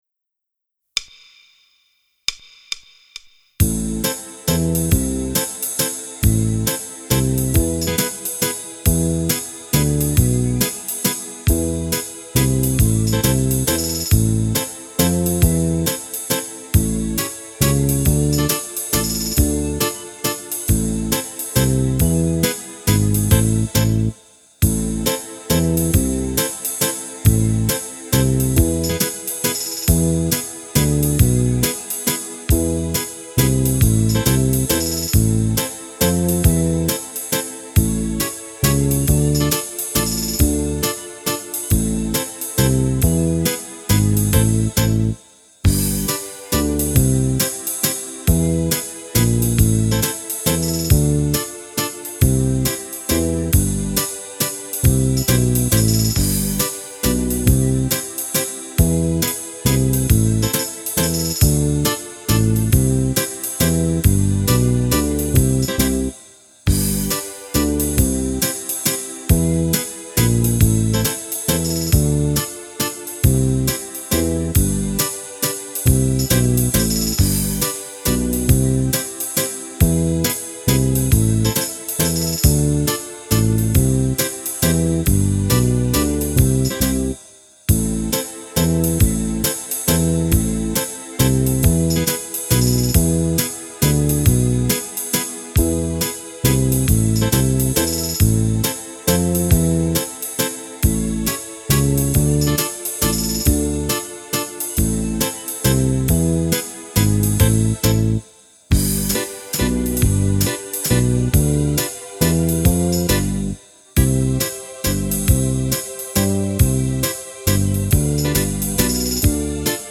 Mazurca variata
Fisarmonica